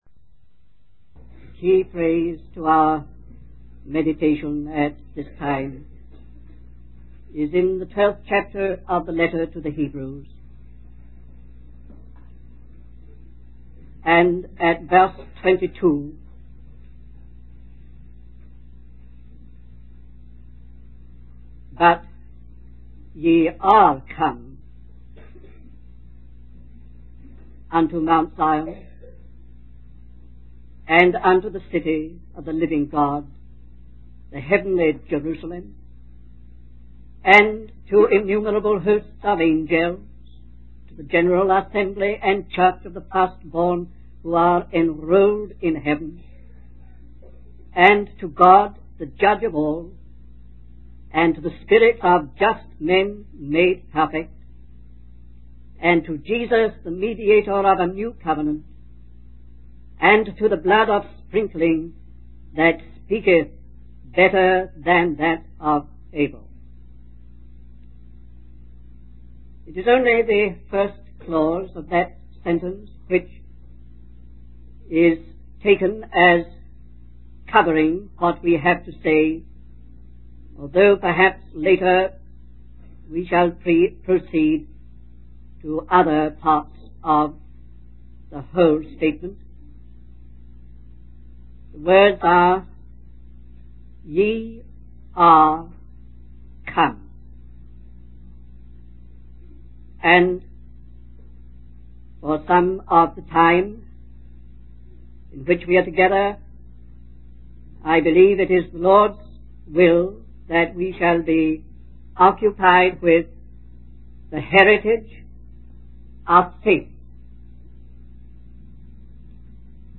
In this sermon, the speaker emphasizes that man was created by God to have a potential friendship with Him and to ultimately experience glory. The message highlights the spiritual significance of the creation story in Genesis, explaining that everything necessary for man's glorious destiny has already been accomplished by God through Christ. The speaker acknowledges that there is a deficiency in our understanding and faith in this truth, urging listeners to seek a deeper appreciation and worship of God.